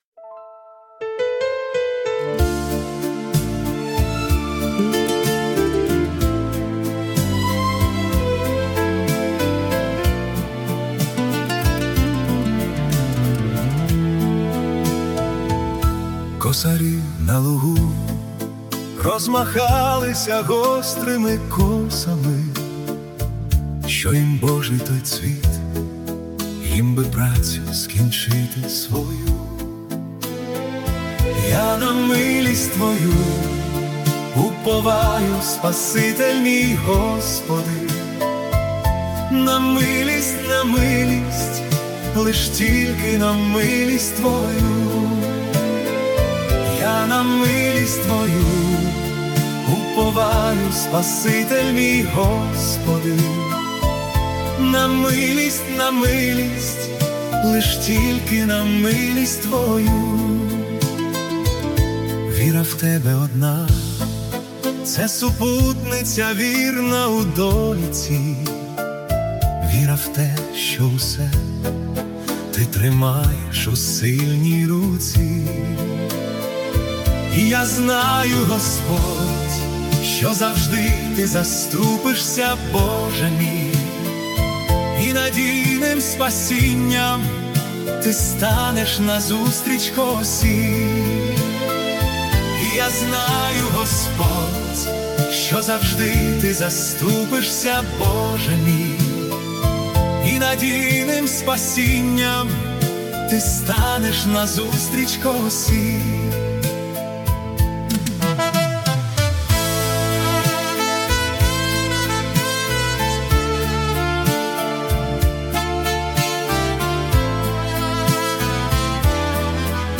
Духовна притча у ритмі класичного вальсу.
Музика, що надихає: Ретро-вальс 🎹
християнська пісня вальс